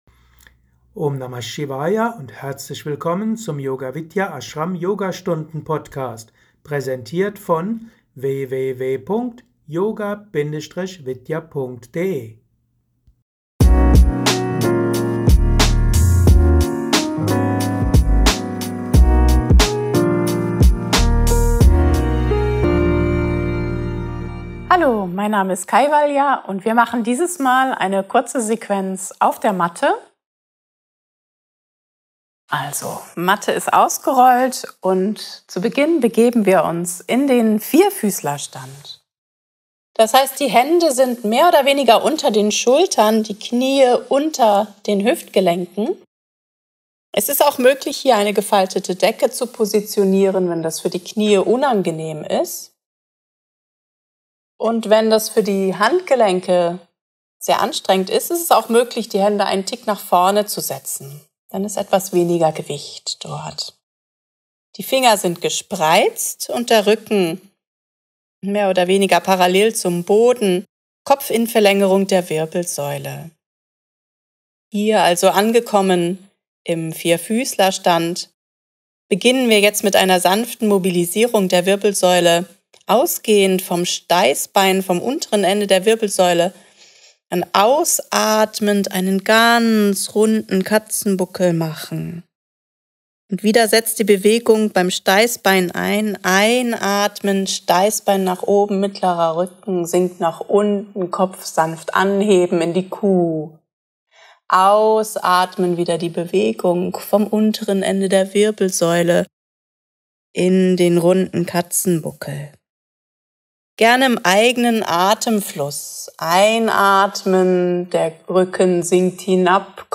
Übungsaudiobeitrag